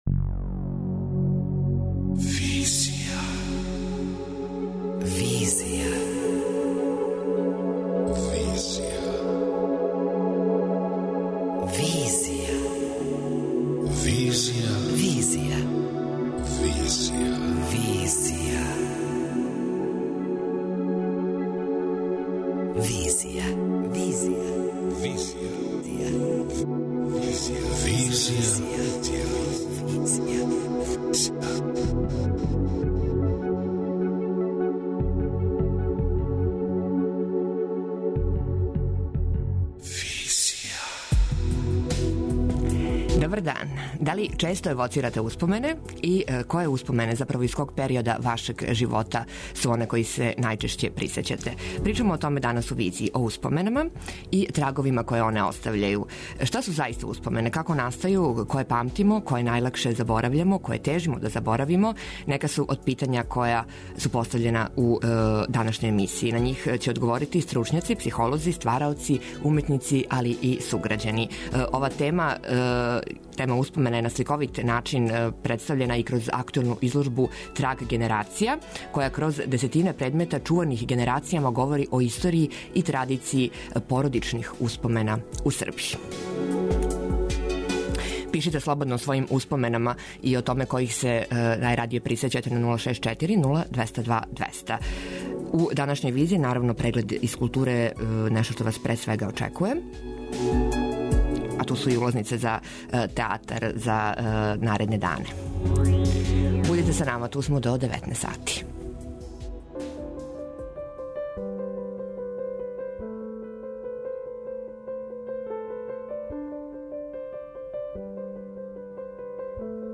Шта су успомене, како настају, које памтимо а које заборављамо, нека су од питања која су постављена у данашњој емисији. На њих одговарају стручњаци-психолози, ствараоци, уметници и суграђани.